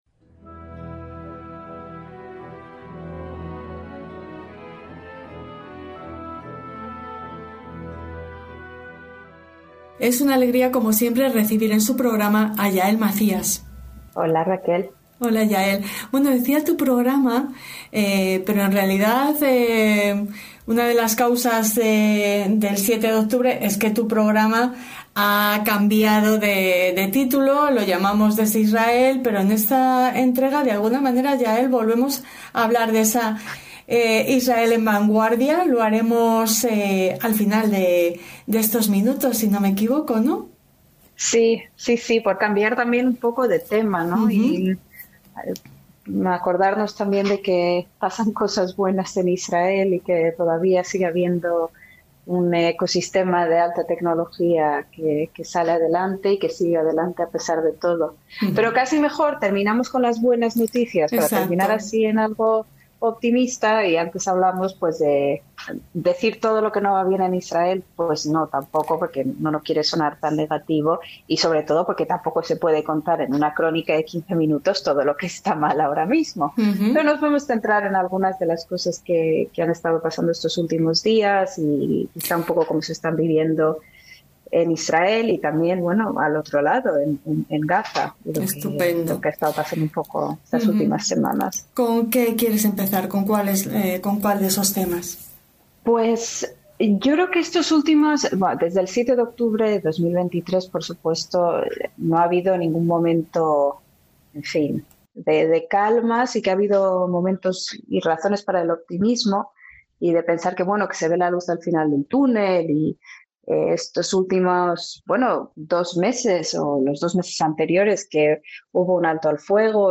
crónica sonora